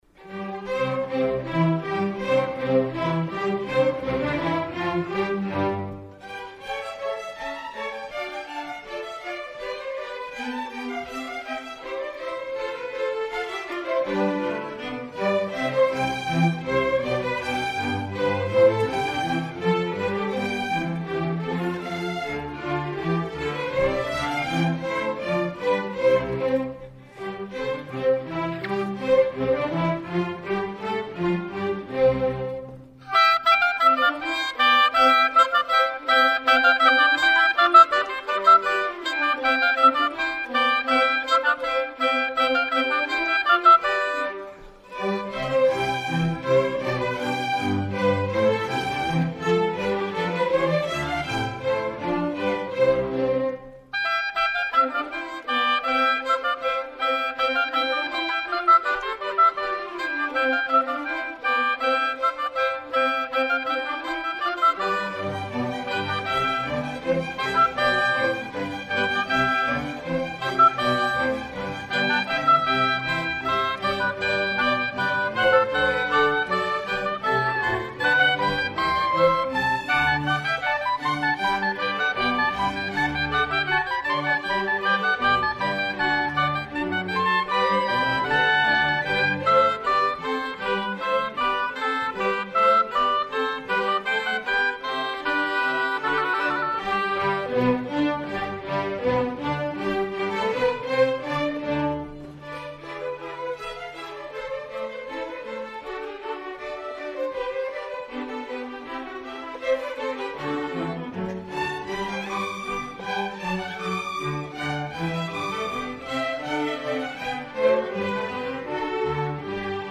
Fall 2016 Concert
Concerto Op. IX no. 9 for two oboes and strings - Tomaso Albinoni
06 Concert for Oboes & Strings - Allegro.mp3